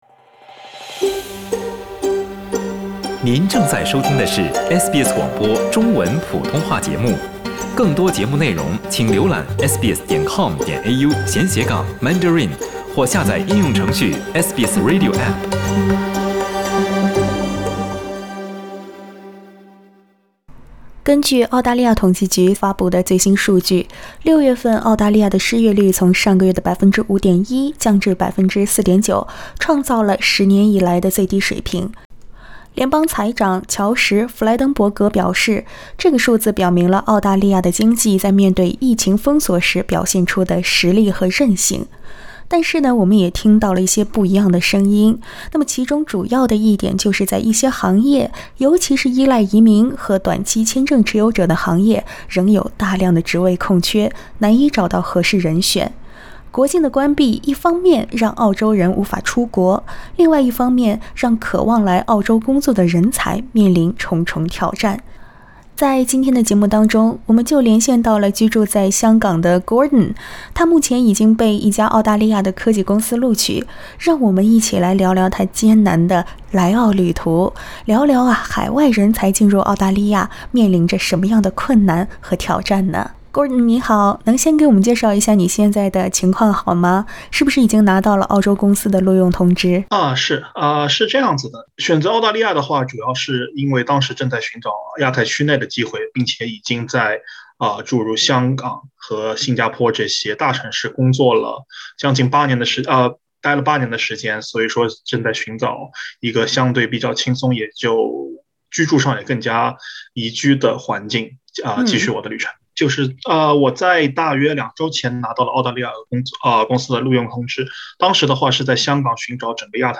（請聽寀訪） 本文系 SBS 中文原創內容，未經許可，不得轉載。